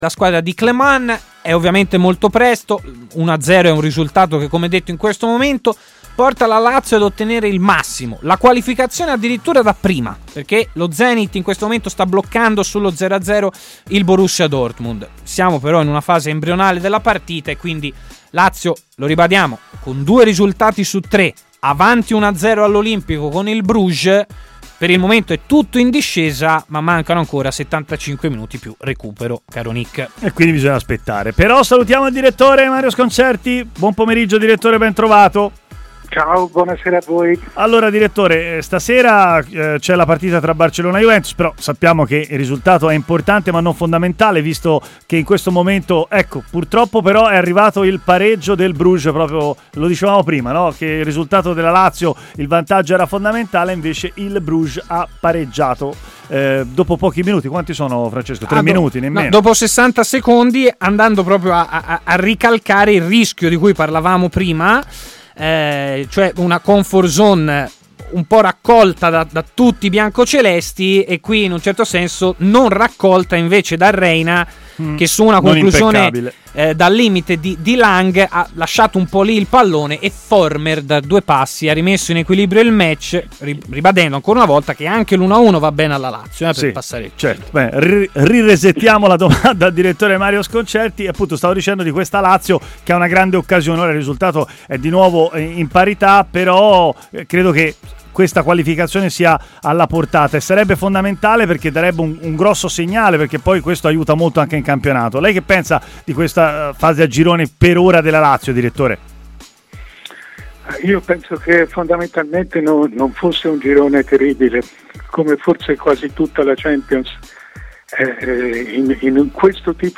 Il direttore Mario Sconcerti è intervenuto a Stadio Aperto